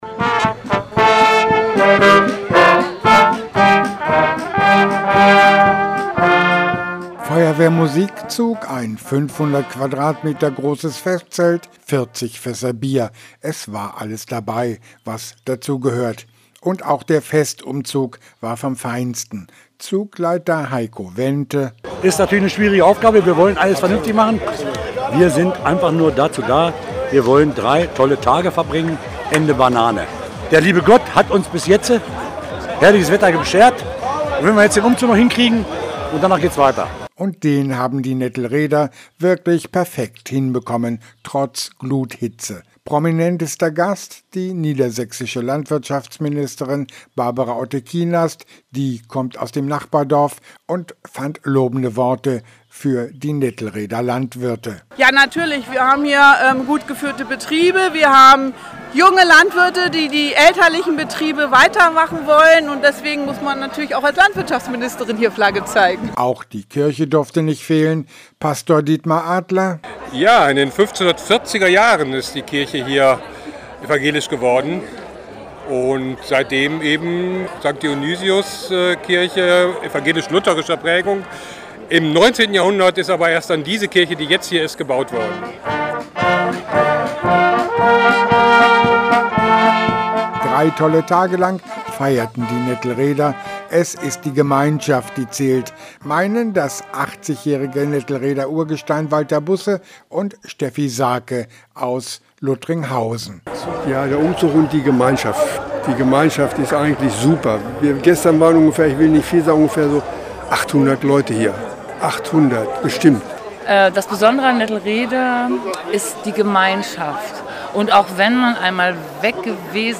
1000-Jahr-Feier im münderschen Ortsteil Nettelrede, ein Bericht